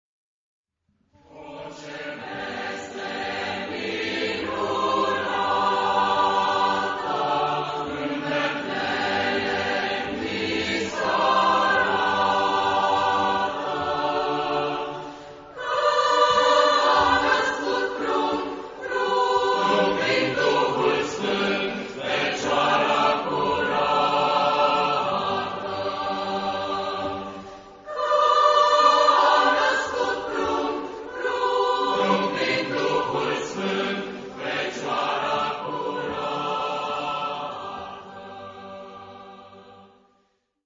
Genre-Style-Forme : Populaire ; Chanson ; Profane
Type de choeur : SATB  (4 voix mixtes )
Tonalité : sol majeur